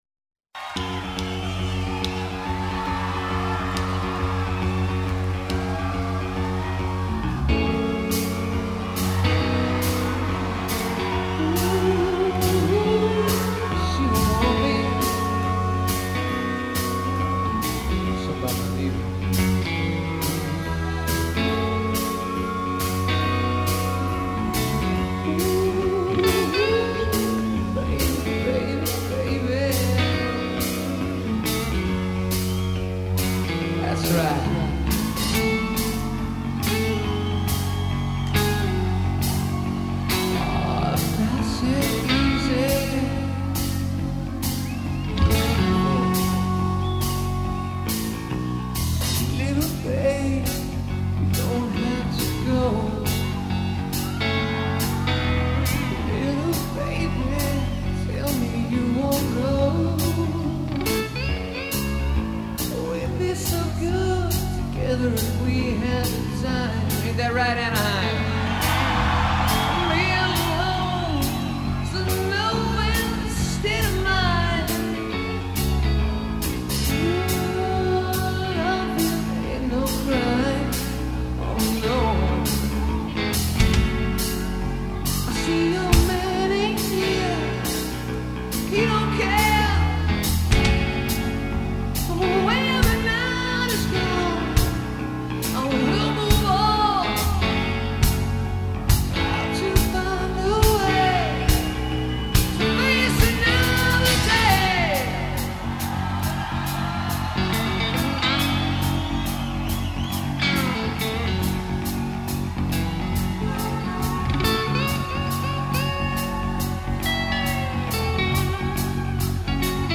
hard rockers